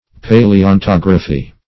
Search Result for " paleontography" : The Collaborative International Dictionary of English v.0.48: Paleontography \Pa`le*on*tog"ra*phy\ (p[=a]`l[-e]*[o^]n*t[o^]g"r[.a]*f[y^]), n. [Paleo- + Gr.